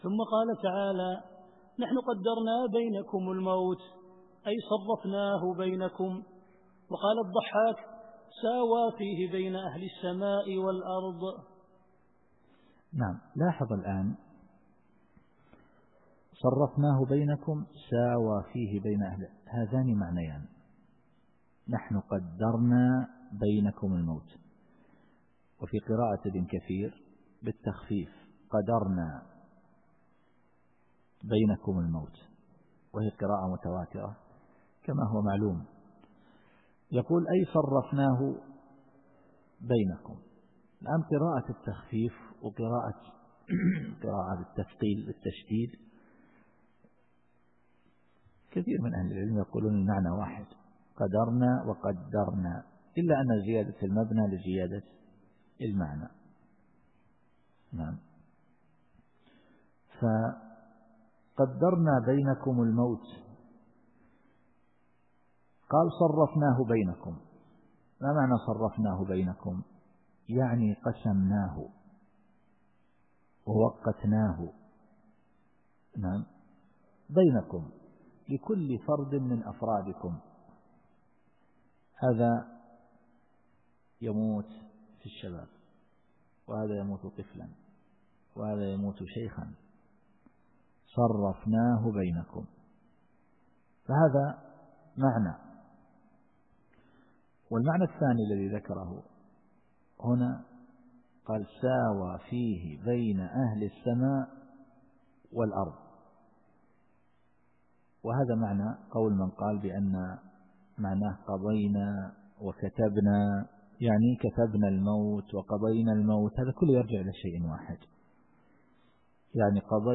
التفسير الصوتي [الواقعة / 62]